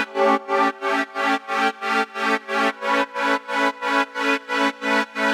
GnS_Pad-MiscB1:8_90-A.wav